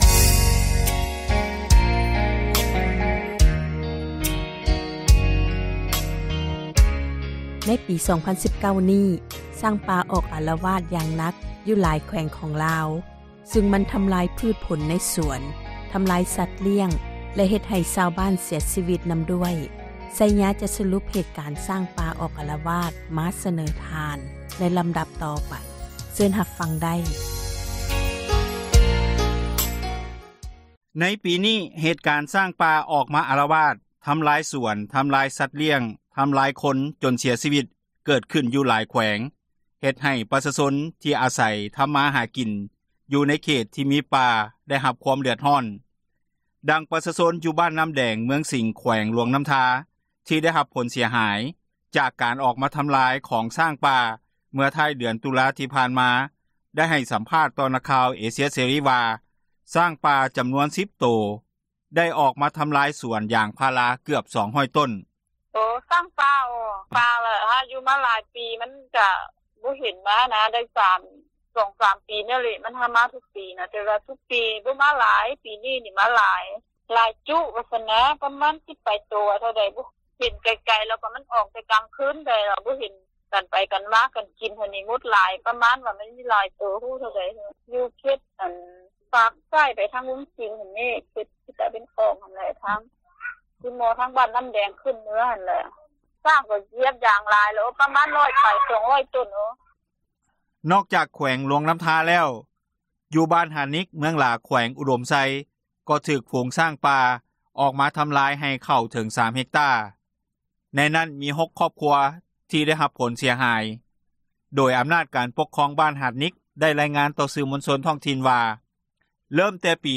ດັ່ງປະຊາຊົນ ຢູ່ບ້ານນໍ້າແດງ, ເມືອງສິງ ແຂວງຫຼວງນໍ້າທາ ທີ່ໄດ້ຮັບ ຜົລເສັຽຫາຍ ຈາກການ ອອກມາທຳຣາຍ ຂອງຊ້າງປ່າ ເມື່ອທ້າຍ ເດືອນຕຸລາ ຜ່ານມາ ໄດ້ໃຫ້ສັມພາດ ຕໍ່ນັກຂ່າວເອເຊັຽເສຣີ ວ່າ:  ຊ້າງປ່າ ຈຳນວນ 10 ປາຍໂຕ ໄດ້ອອກ ມາທຳຣາຍ ສວນຢາງພາຣາ ເກືອບ 200 ຕົ້ນ.